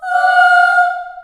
Index of /90_sSampleCDs/USB Soundscan vol.28 - Choir Acoustic & Synth [AKAI] 1CD/Partition A/03-CHILD AHS
F4 CHS AH -L.wav